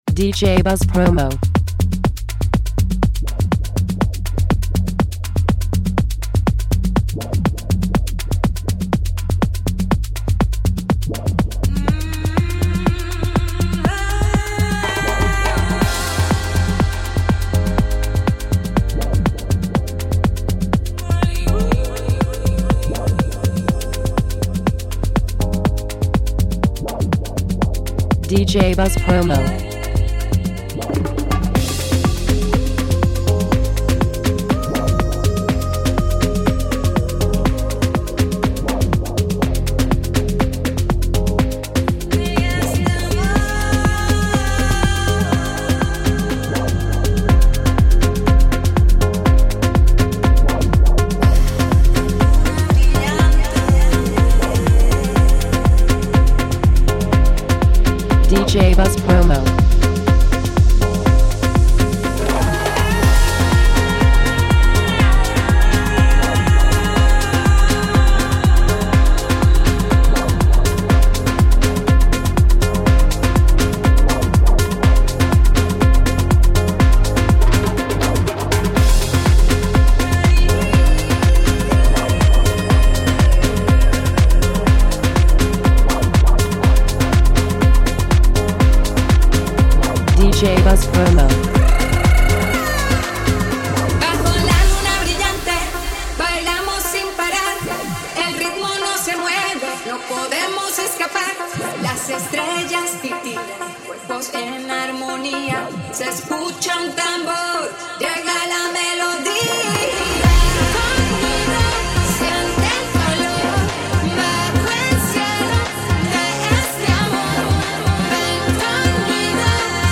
Afro House